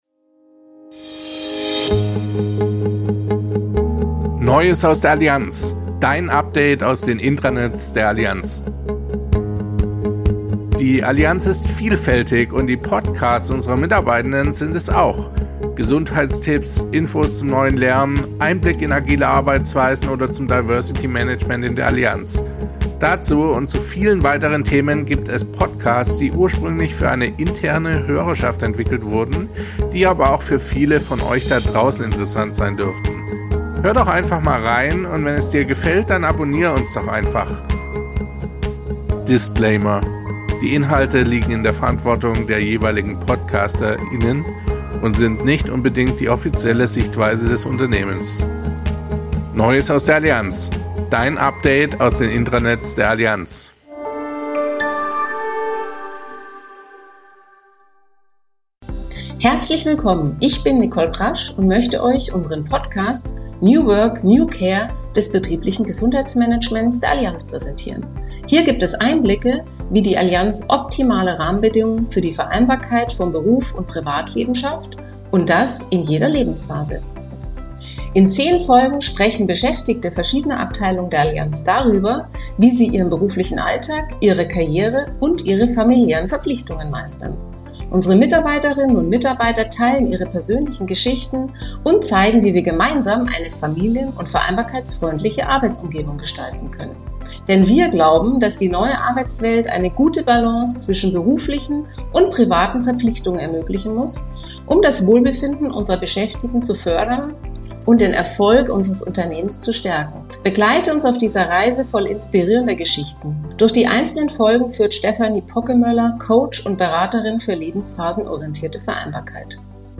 In dieser Podcastfolge teilen die beiden Gesprächspartnerinnen persönliche Tipps für einen erfolgreichen Neustart in den Beruf. Wir sprechen außerdem darüber, warum der Austausch mit Kollegen und Kolleginnen und Netzwerken so wichtig ist.